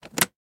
Lever_Off.wav